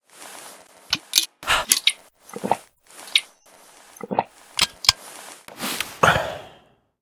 drink_flask3.ogg